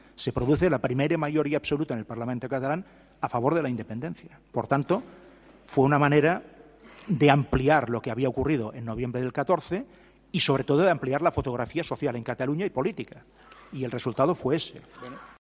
Artur Mas ha comparecido como testigo en el juicio y ha subrayado que el protagonismo de la sociedad civil organizada o de la gente a pie de calle fue "determinante" para organizar ese referéndum, declarado ilegal por el Tribunal Constitucional.